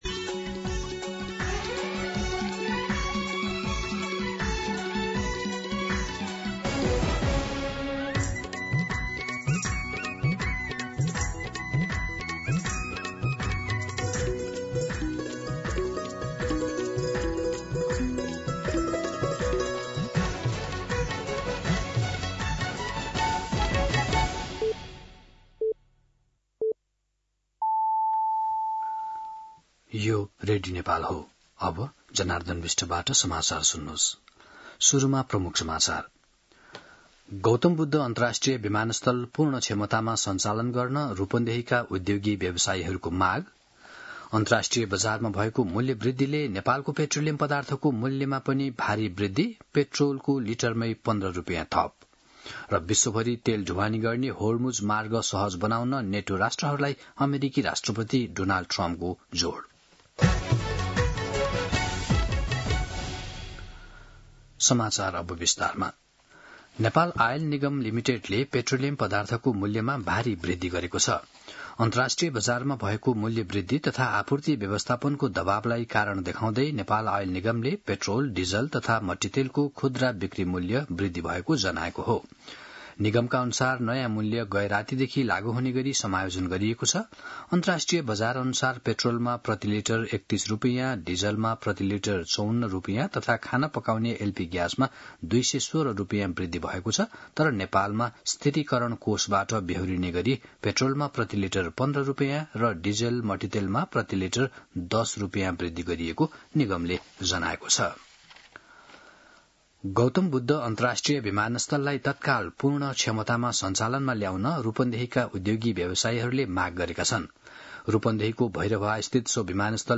दिउँसो ३ बजेको नेपाली समाचार : २ चैत , २०८२
3pm-Nepali-News.mp3